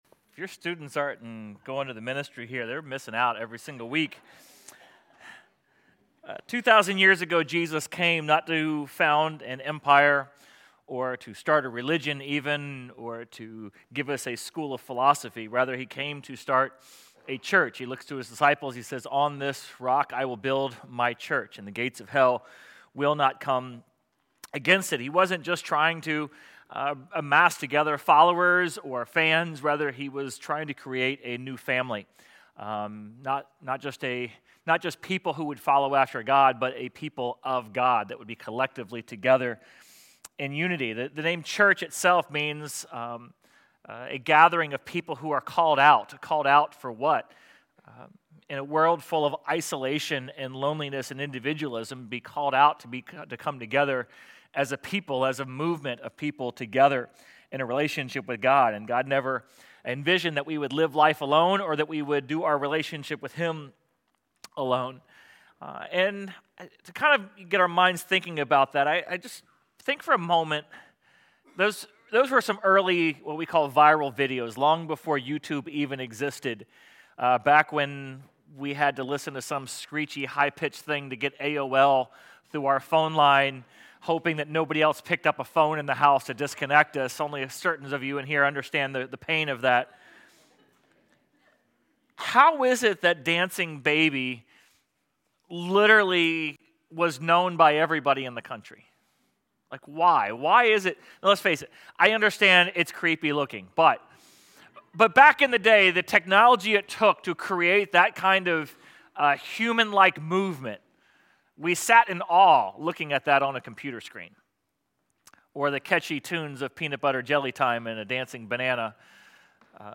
Sermon_11.2.25.mp3